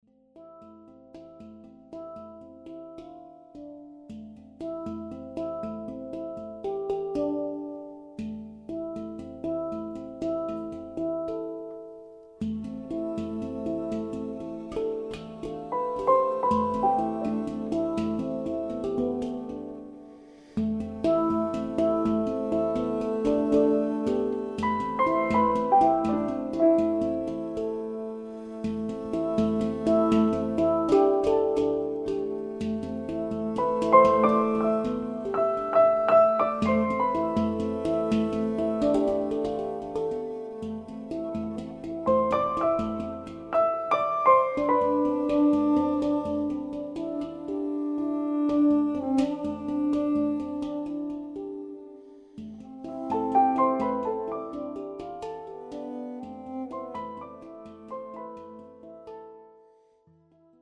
5:10 Violine, Hang, Piano